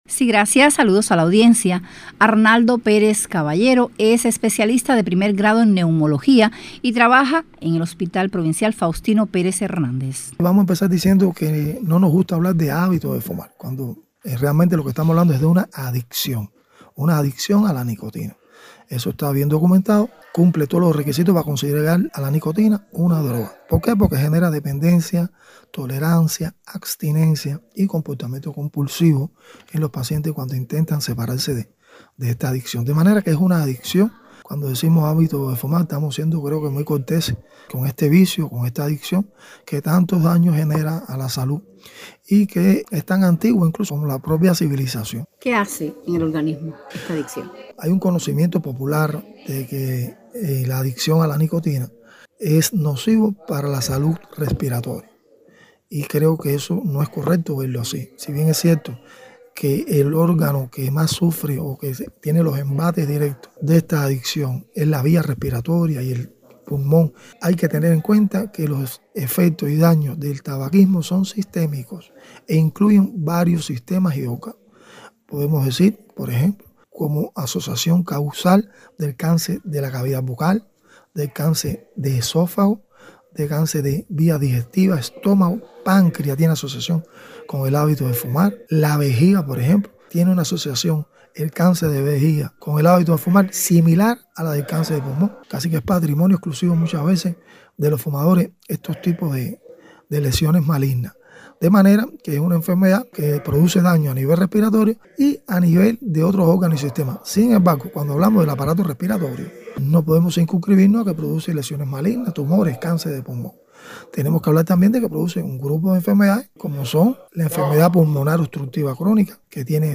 Por ello conversamos con